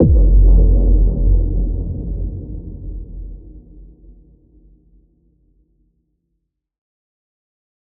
Sounddesign, ambience and implementation:
CSSF1-IMPACT-2.wav